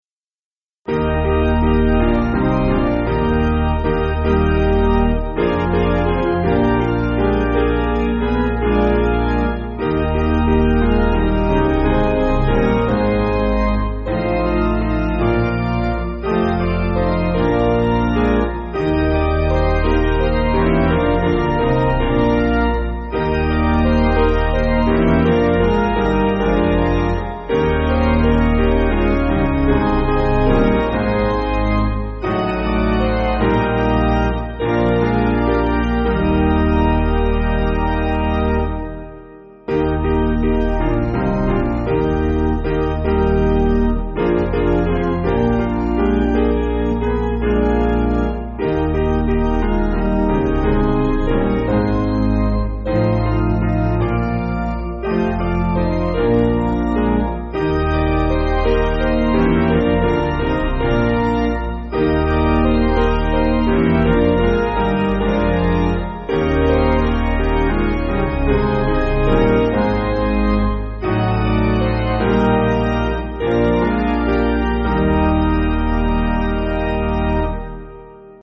Basic Piano & Organ
(CM)   5/Eb